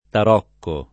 vai all'elenco alfabetico delle voci ingrandisci il carattere 100% rimpicciolisci il carattere stampa invia tramite posta elettronica codividi su Facebook tarocco [ tar 0 kko ] s. m. («carta da gioco»; «arancia»); pl.